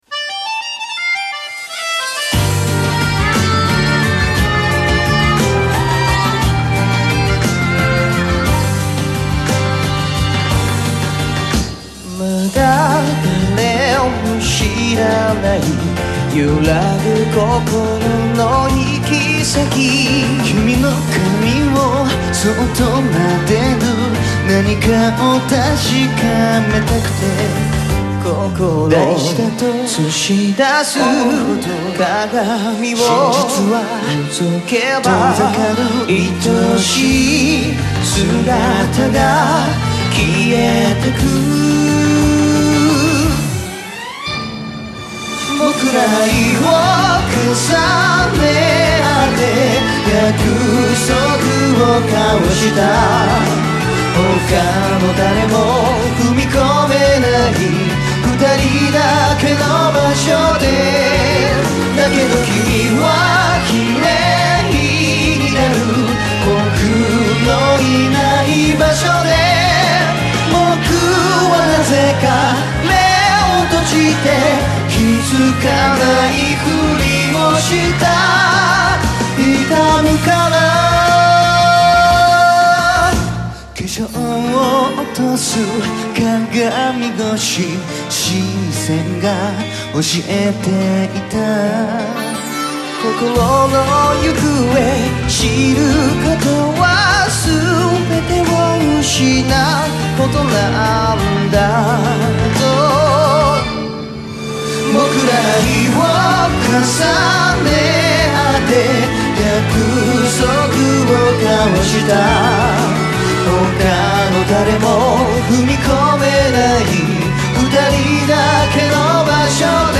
완전 두 사람의 그..... 눈물을 머금은 목소리에 깊디깊은 호소력이 절절 철철 콸콸 !!!ㅠㅠ